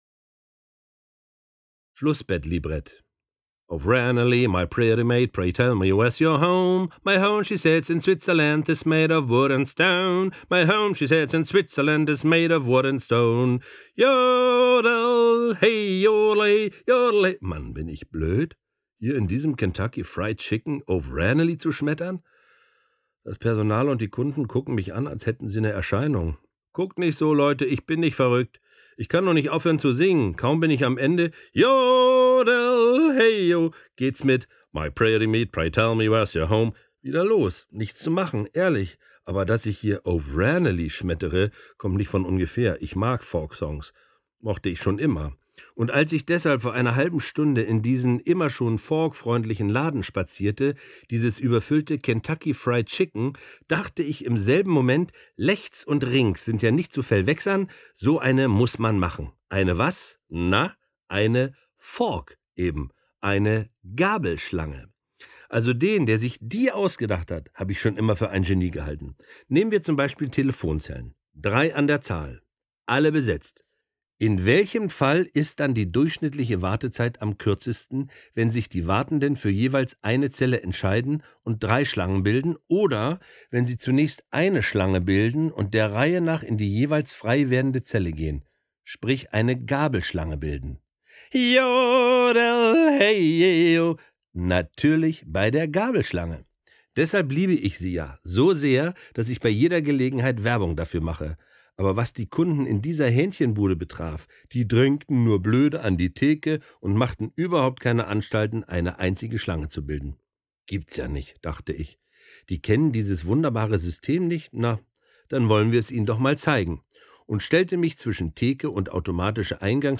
Charly Hübner (Sprecher)